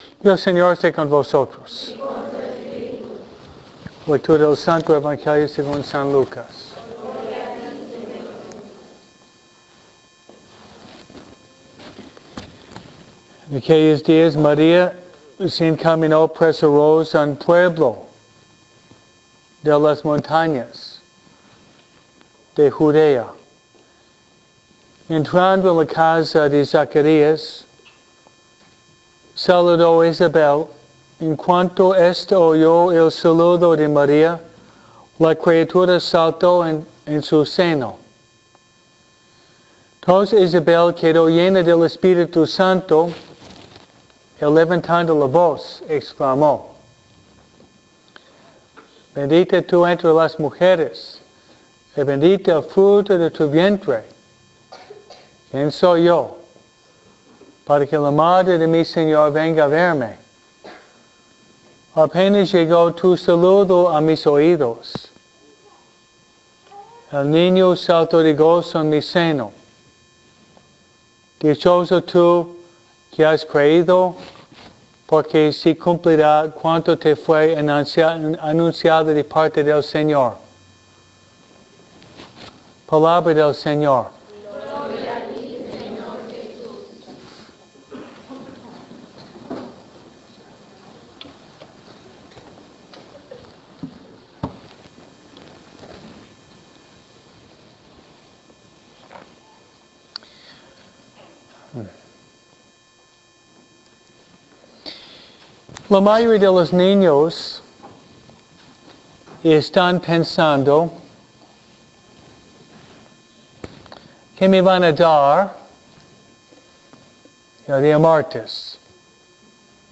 002S-PREPARAR-NAVIDAD-MISA-NINOS.mp3